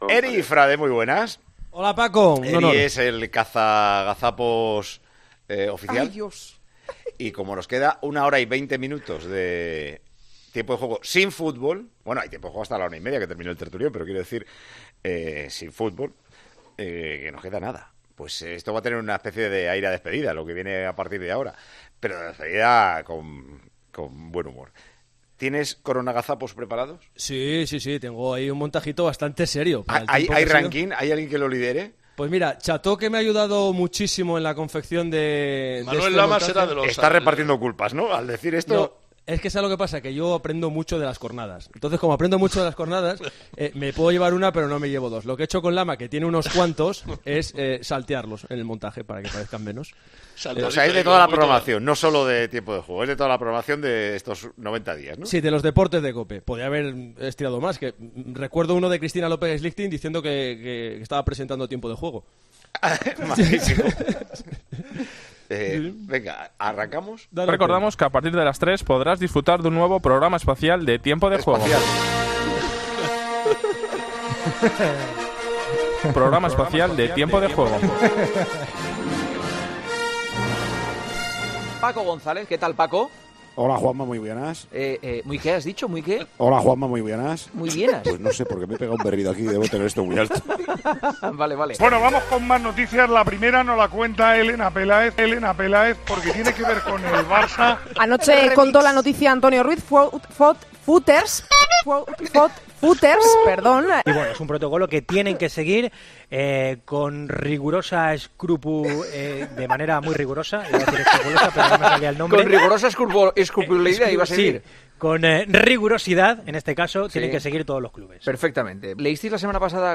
La programación deportiva de la Cadena COPE durante el Estado de Alarma no nos ha privado de seguir metiendo la 'gamba' de vez en cuando tanto en antena.
Con Paco González, Manolo Lama y Juanma Castaño